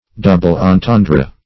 Search Result for " double-entendre" : The Collaborative International Dictionary of English v.0.48: Double-entendre \Dou"ble-en*ten"dre\, n. [F. double double + entendre to mean.
double-entendre.mp3